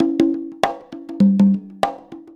100 CONGAS01.wav